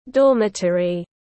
Ký túc xá tiếng anh gọi là dormitory, phiên âm tiếng anh đọc là /ˈdɔːmətri/.
Dormitory /ˈdɔːmətri/
Dormitory.mp3